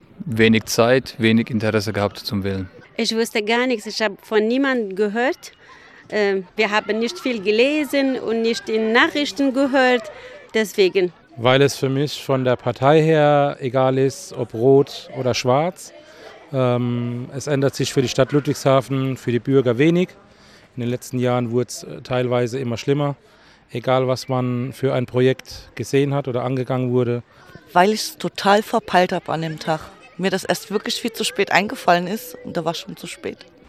Unterwegs beim Sportverein Ludwigshafener SC
Dienstagabend beim Sportverein LSC in der Gartenstadt: Die Fußball-Kids trainieren, die Eltern stehen am Spielfeldrand und schauen zu. Auch von ihnen wollen wir wissen: "Haben Sie gewählt?"